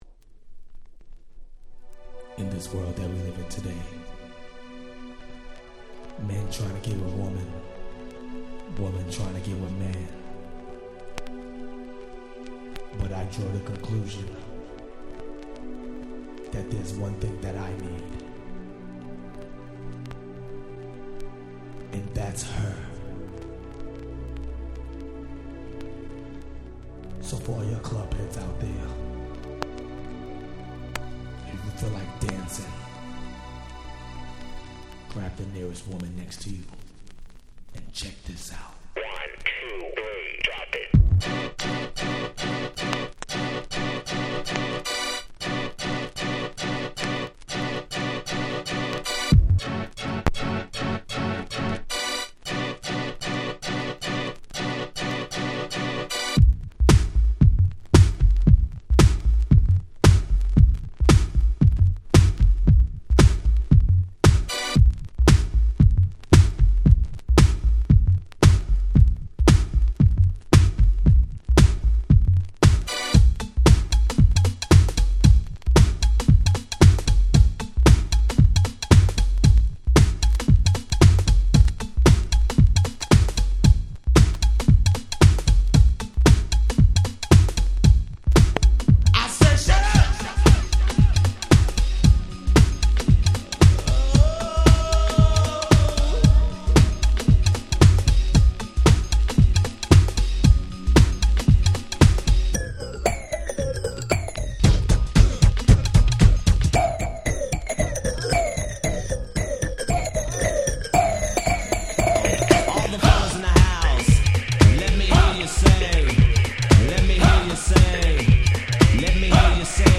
91' Smash Hit New Jack Swing !!
バッキバキで攻撃的過ぎるBeat、熱くほとばしるVocal、最高です！！
90's NJS R&B ニュージャックスウィング ハネ系